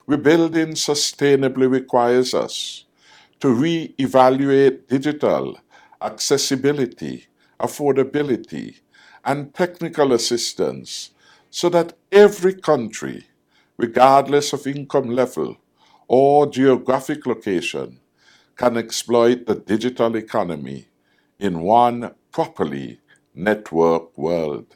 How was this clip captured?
During the 76 th UN General Assembly